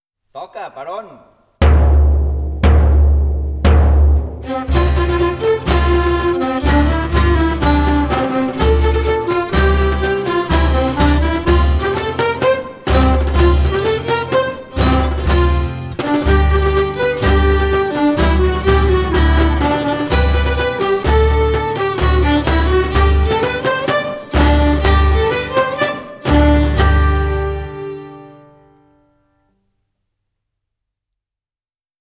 24.1 PASSADA BALL DE DAMES I VELLS Músics del Ball de Dames i Vells
Santa Tecla Tarragona